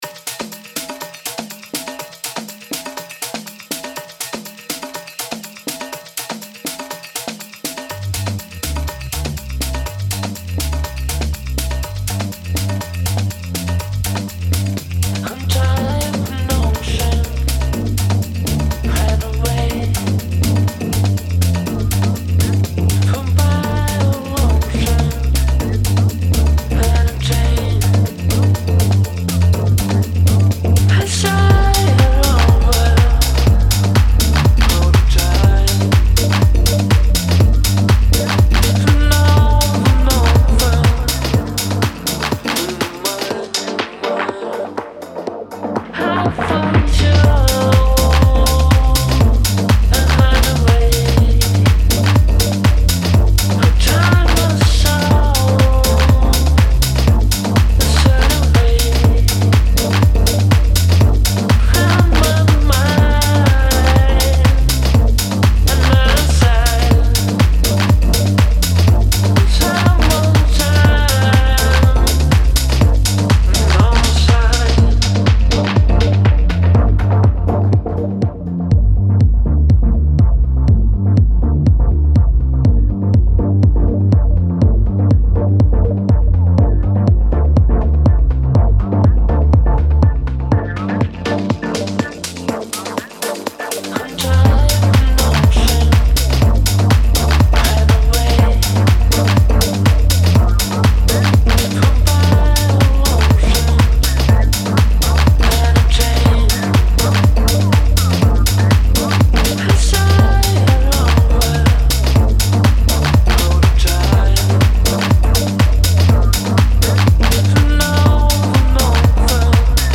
house, afro
Сейчас пел в МКЭ-2 У меня есть кое какие мнения по поводу динамики, но подожду ваших комментариев) Ну и по сведению ,балансу и пр. очень хочется комментариев услышать )...